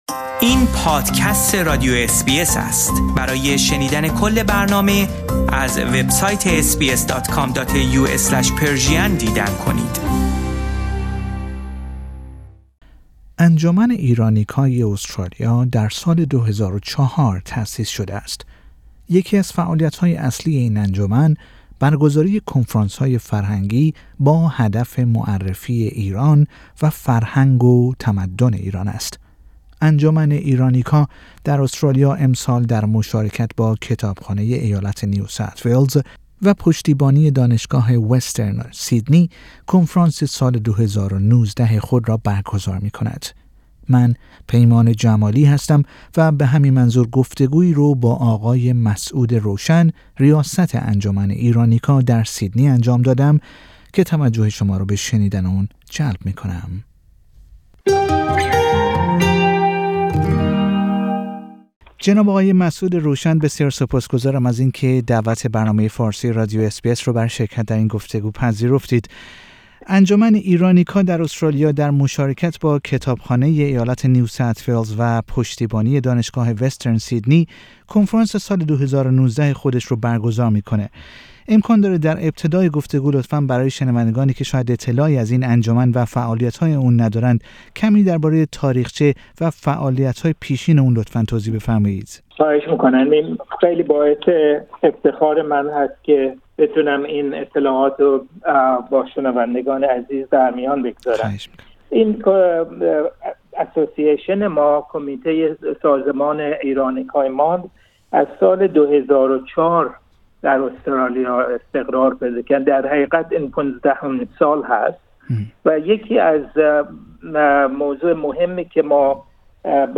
در گفتگو با برنامه فارسی رادیو اس بی اس